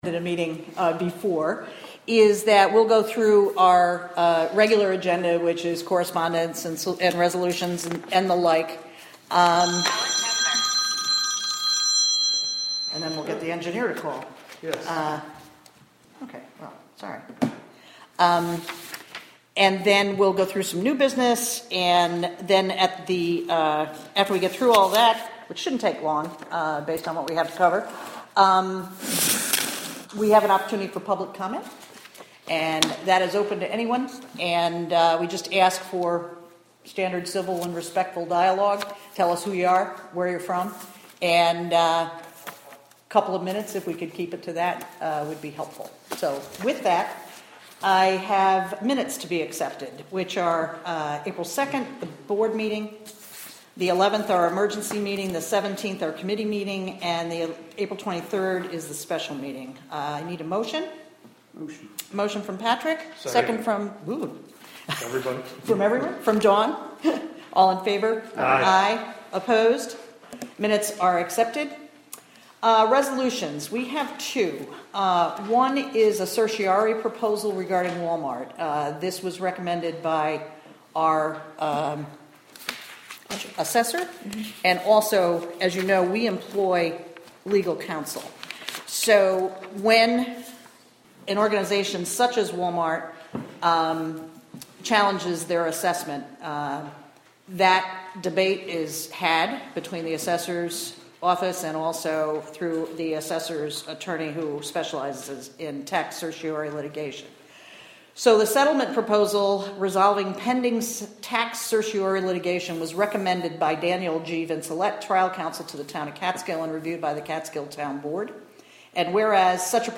Recorded from a live webstream created by the Town of Catskill through the Wave Farm Radio app. Monthly Town Board meeting.